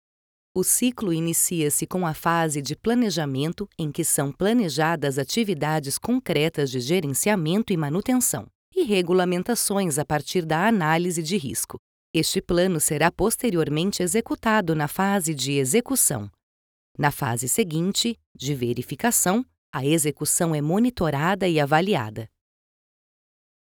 E_Learning
With a versatile, mature, and pleasant voice, I am able to adapt my narration style to the needs of the project, creating a unique and engaging experience for the listener.
With an excellent acoustic treatment system that offers excellent quality.